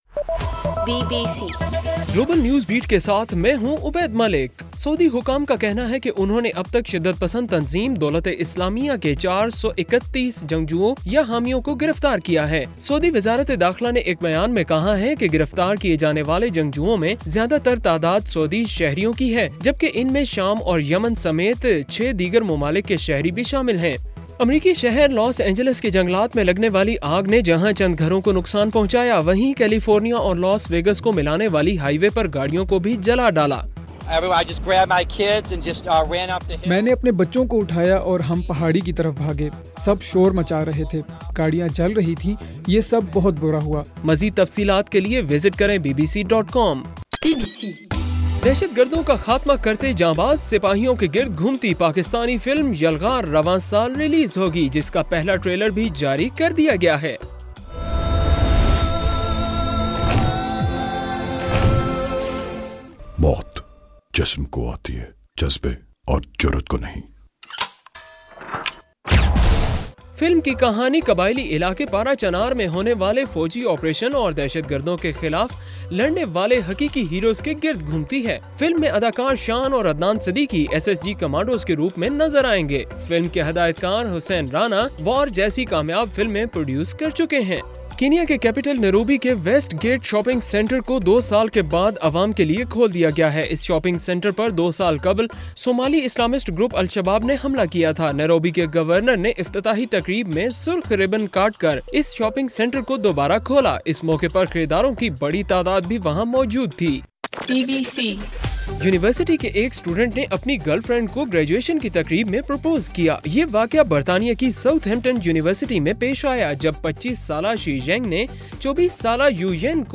جولائی 18: رات 12 بجے کا گلوبل نیوز بیٹ بُلیٹن